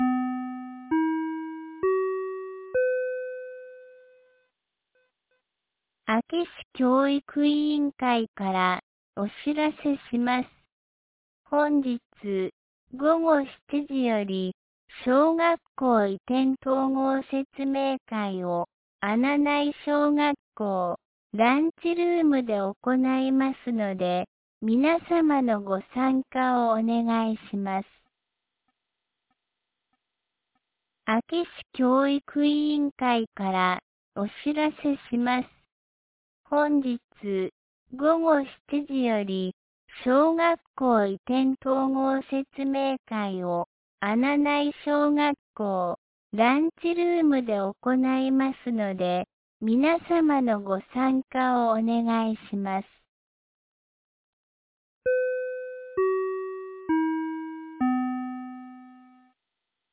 2026年02月03日 12時56分に、安芸市より穴内へ放送がありました。